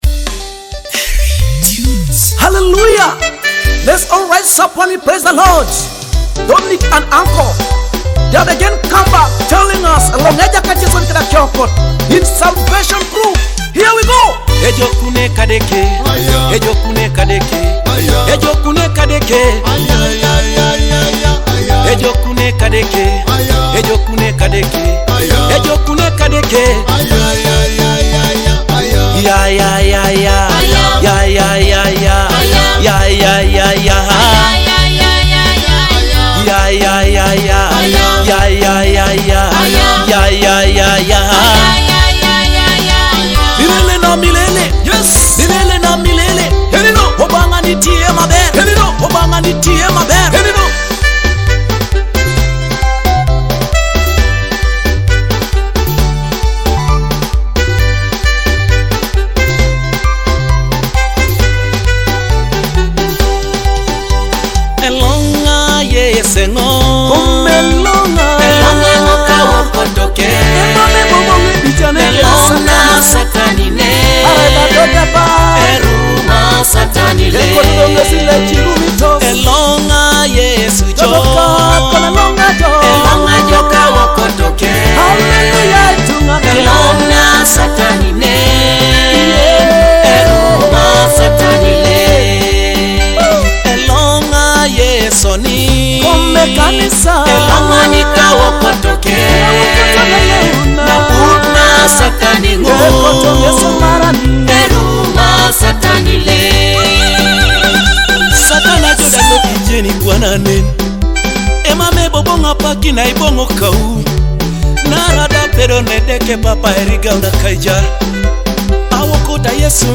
powerful and soul-stirring Ugandan gospel song
With uplifting instrumentation and moving lyrics